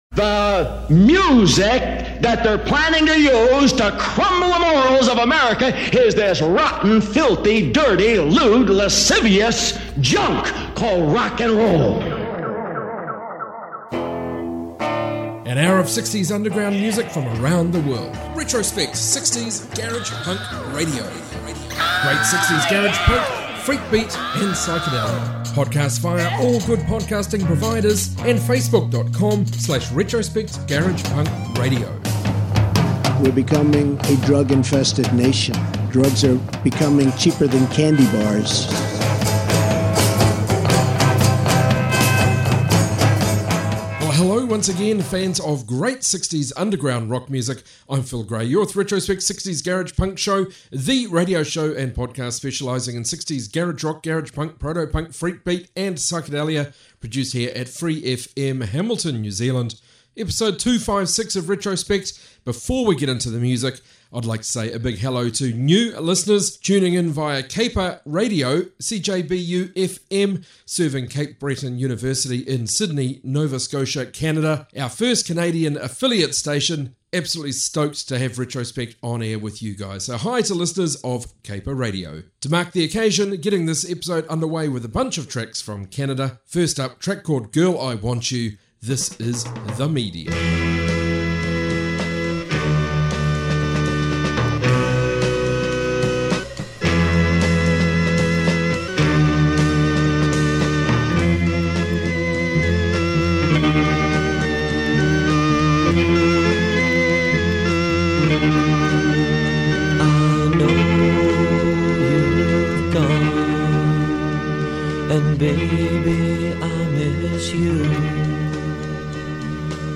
60s garage rock garage punk proto punk freakbeat